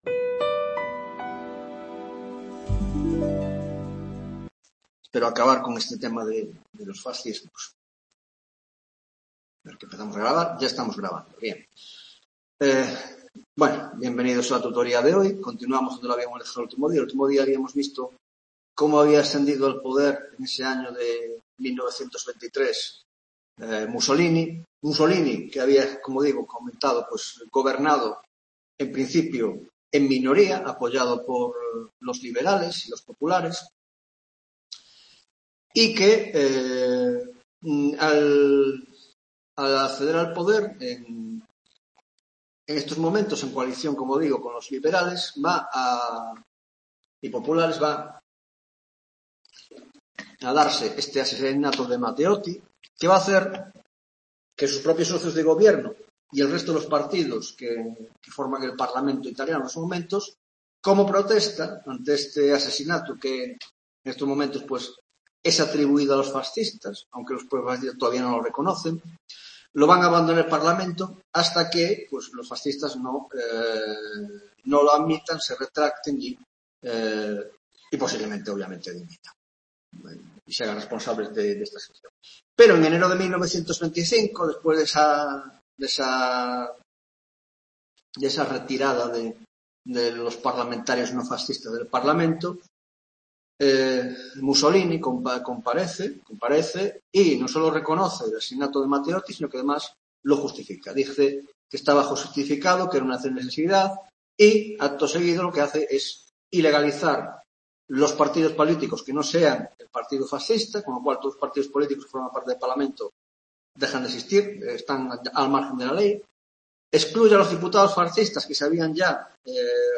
20ª tutoría de Historia Contemporánea